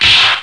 1 channel
smashy1.mp3